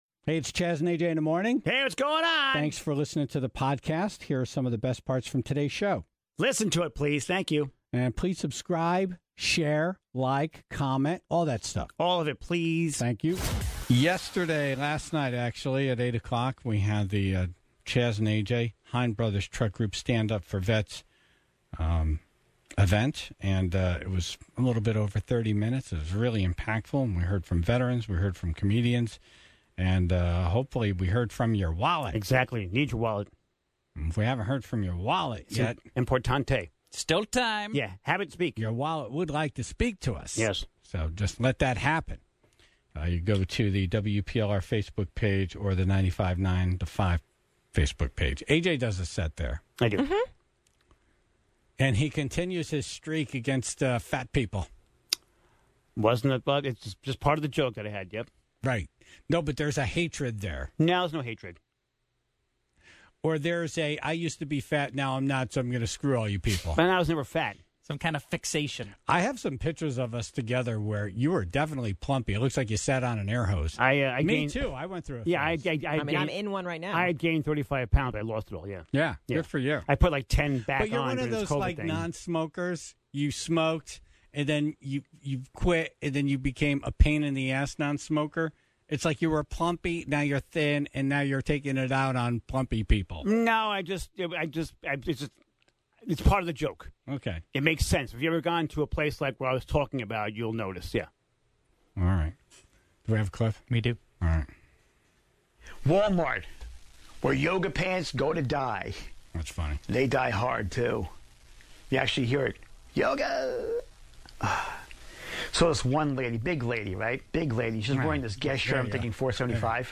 The Tribe was given the green light to call in about anything, as long as they began their call with "Fun Fact."